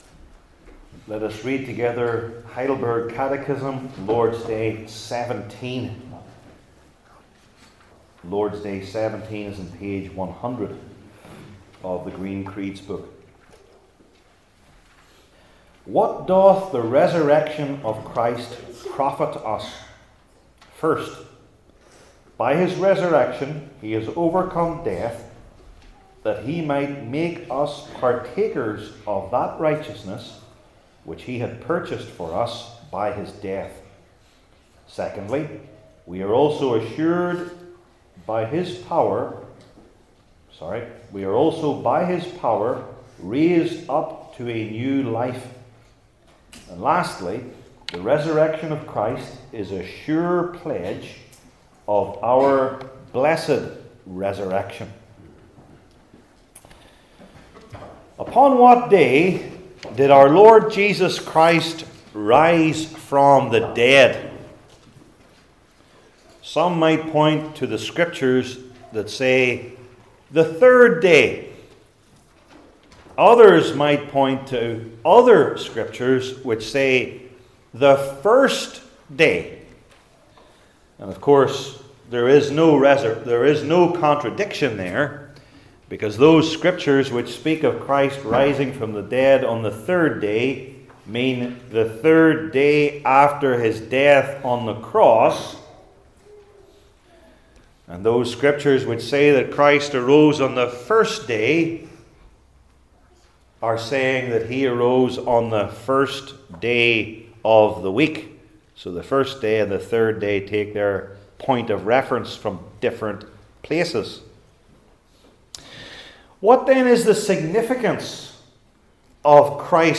Heidelberg Catechism Sermons I. The Timing II.